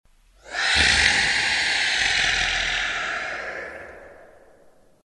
Скачивайте рыки, рев, тяжелое дыхание и крики фантастических существ в формате MP3.
Шипение и рычание чудища